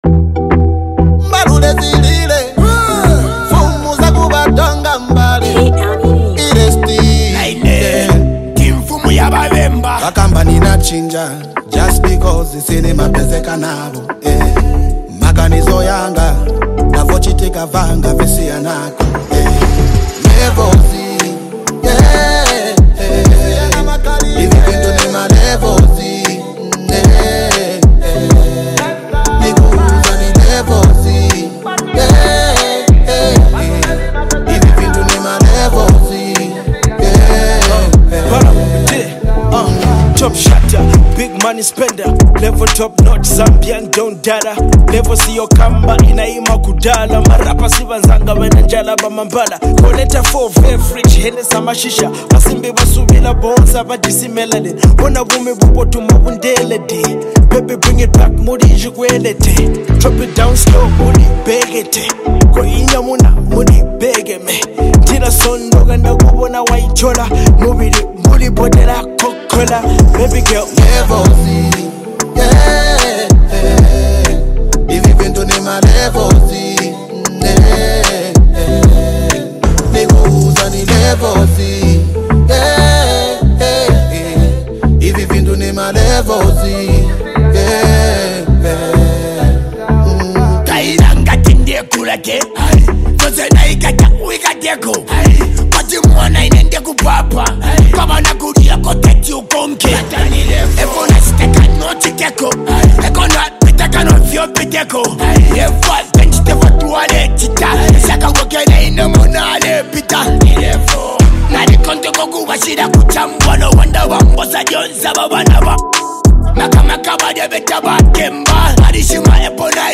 As one of the key players in the Zambian hip-hop scene
an irresistible blend of upbeat energy and catchy lyrics